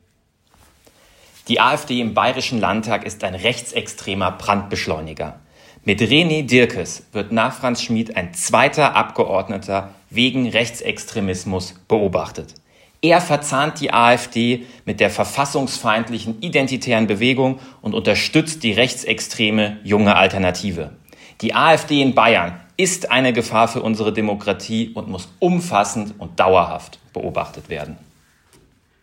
Florian Siekmann, Sprecher für Inneres:
Audio-Statement von Florian Siekmann zum Download